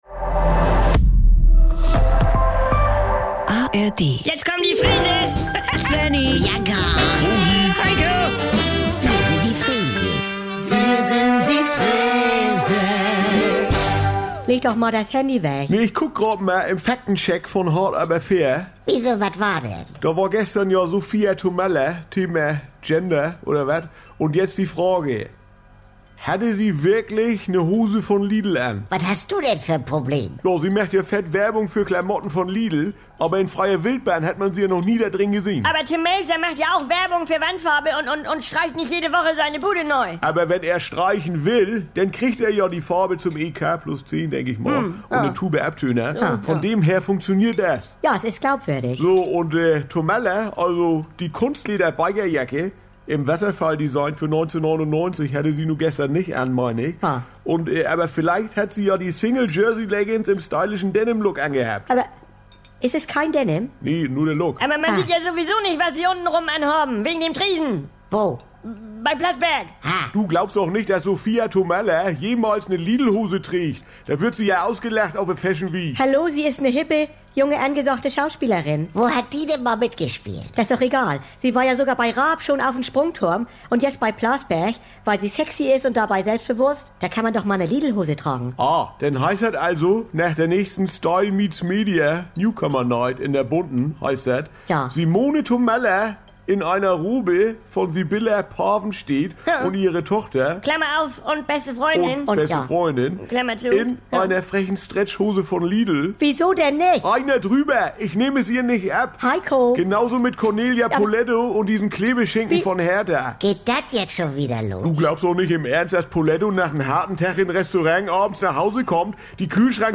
NDR 2 Saubere Komödien Unterhaltung Komödie NDR Freeses Comedy